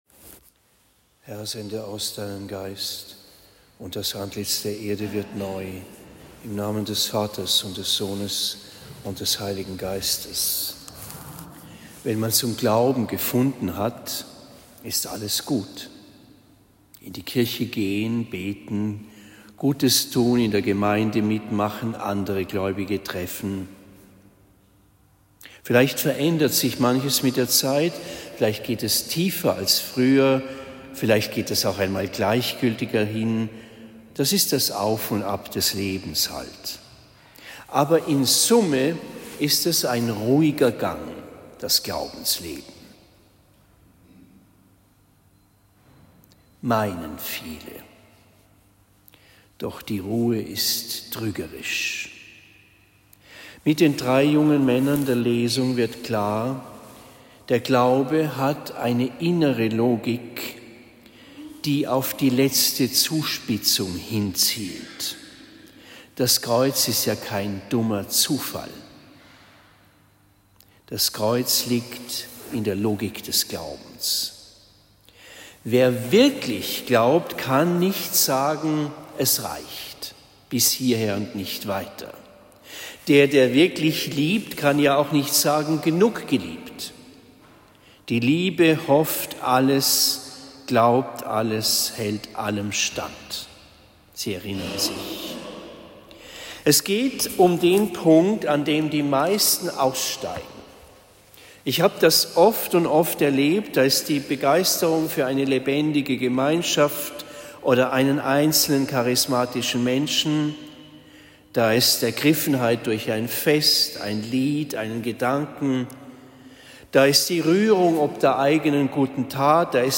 Predigt am 20. März 2024 in Marktheidenfeld St.-Laurentius